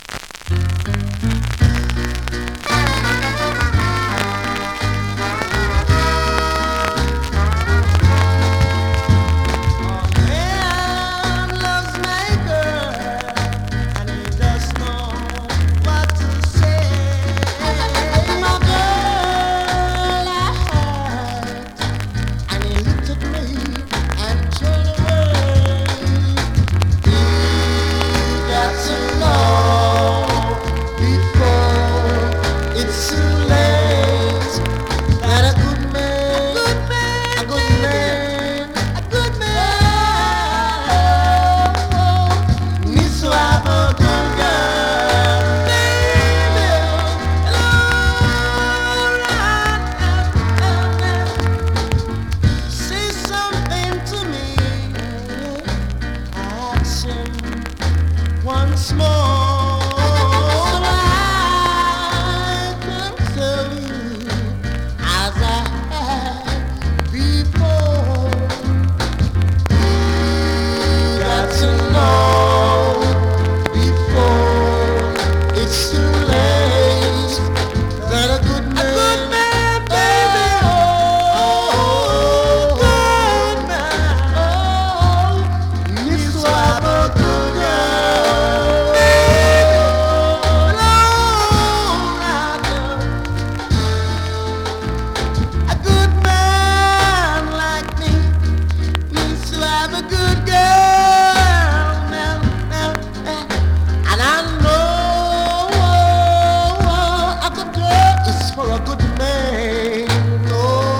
SOUL!!
スリキズ、ノイズそこそこあります。